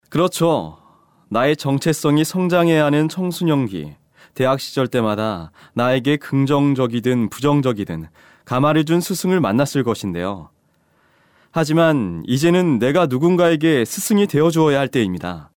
Male Professional Voice Over Talent | VoicesNow Voiceover Actors